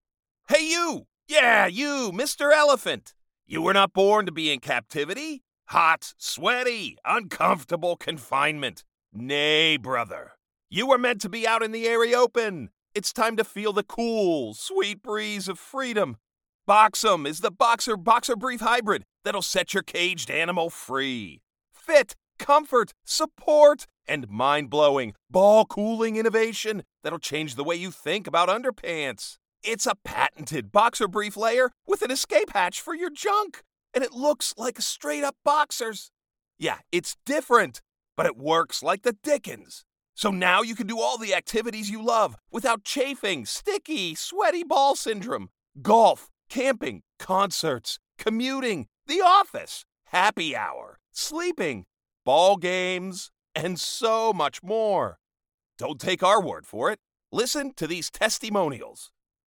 Professional American male voice. Conversational, guy next door, instructional, strong and occasionally snarky!
Snarky ad for Boxem Briefs!
Middle Aged